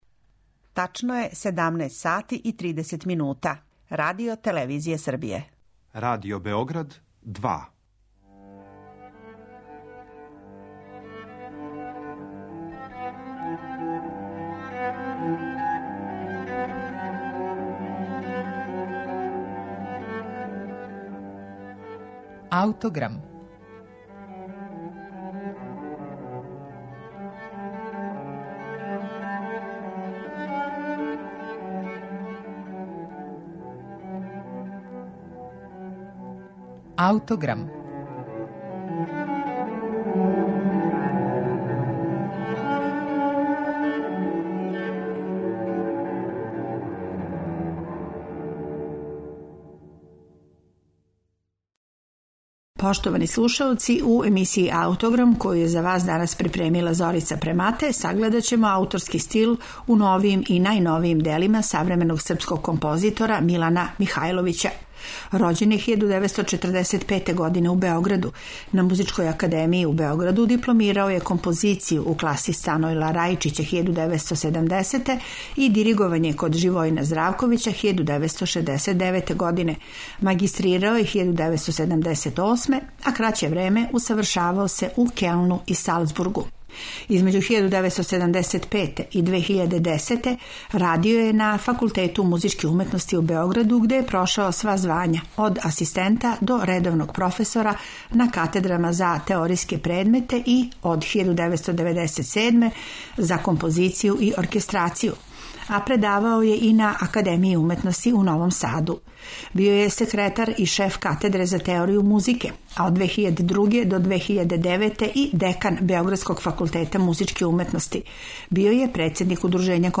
камерне композиције
Снимци су остварени на концерту